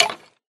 skeletonhurt1.ogg